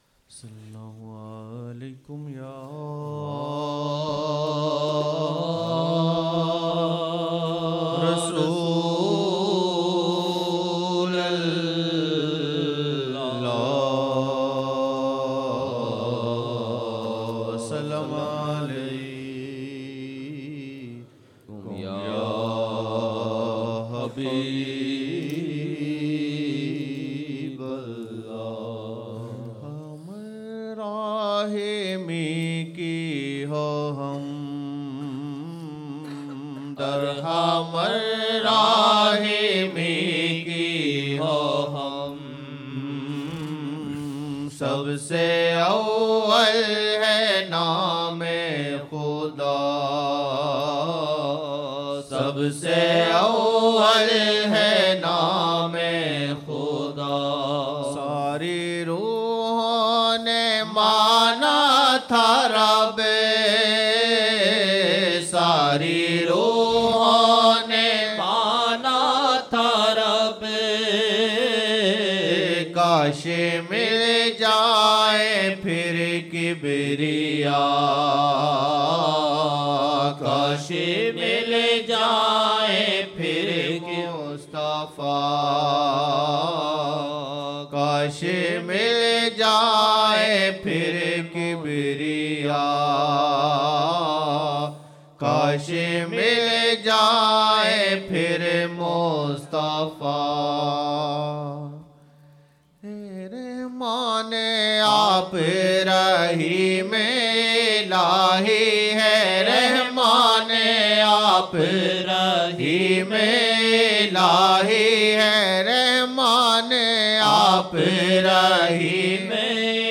Hamd: (Hain Rahmaan Aap, Raheem Ilaahi — woh kya cheez qudrat ki teri paray hai)
Hamd: (Hain Rahmaan Aap, Raheem Ilaahi — woh kya cheez qudrat ki teri paray hai) Fajar 03 Jan 2007 Old Naat Shareef